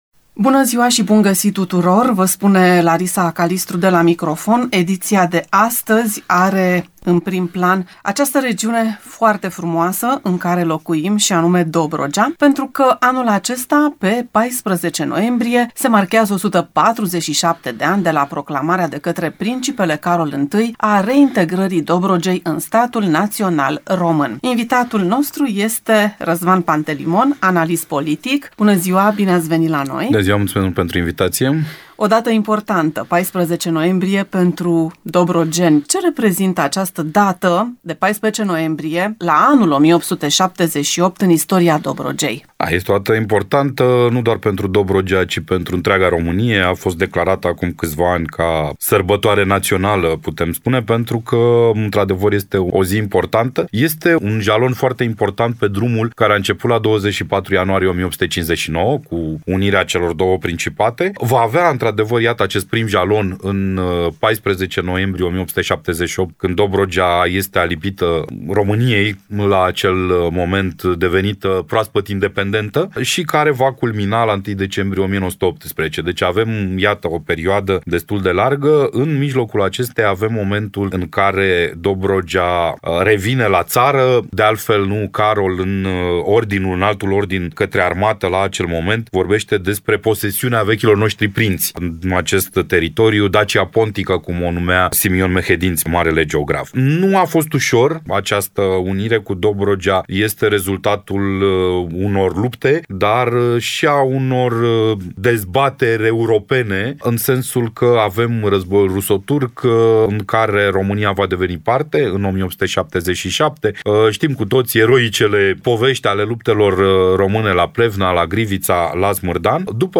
Istoricul Adrian Cioroianu a intervenit telefonic în emisiune, subliniind faptul că 14 noiembrie 1878 este ziua în care, oficial, Dobrogea a intrat în componența statului român.